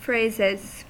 Ääntäminen
Ääntäminen US Tuntematon aksentti: IPA : /ˈfɹeɪ.zɪz/ Haettu sana löytyi näillä lähdekielillä: englanti Käännöksiä ei löytynyt valitulle kohdekielelle. Phrases on sanan phrase monikko.